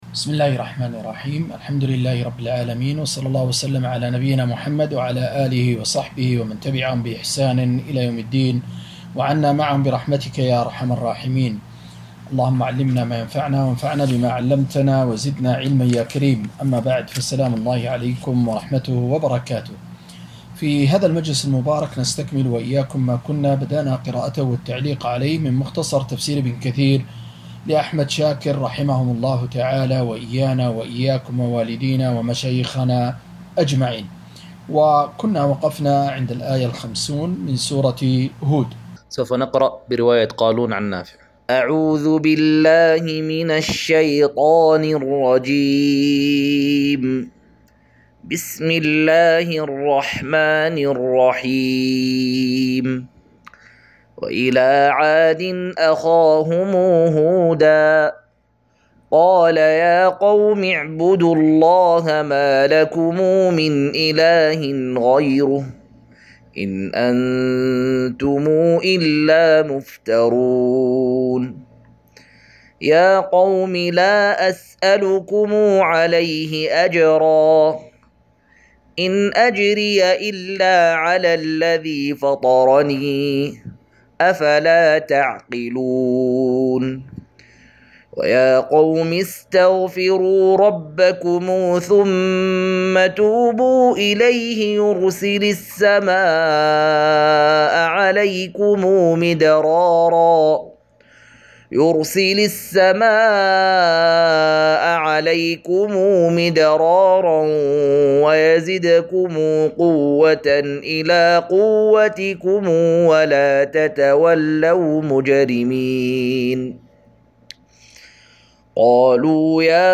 215- عمدة التفسير عن الحافظ ابن كثير رحمه الله للعلامة أحمد شاكر رحمه الله – قراءة وتعليق –